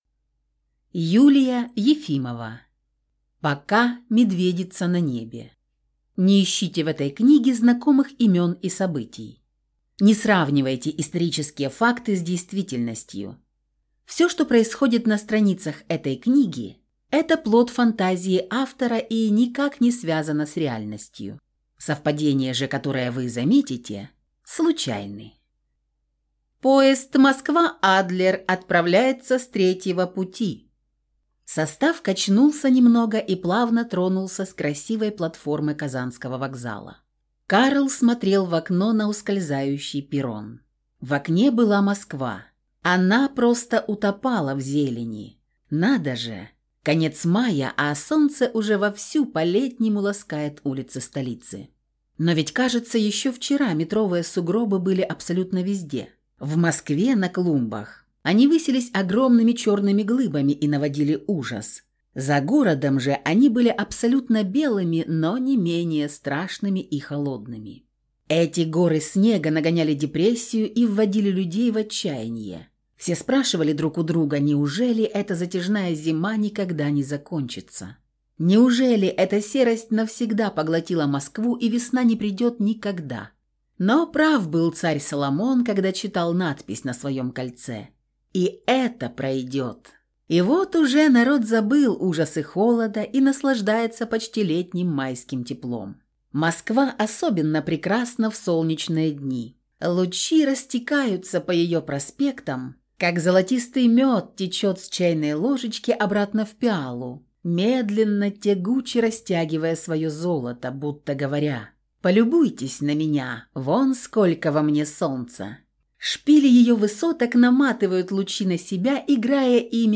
Аудиокнига Пока медведица на небе | Библиотека аудиокниг